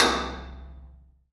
IMPACT_Metal_Tank_Stick_RR2_mono.wav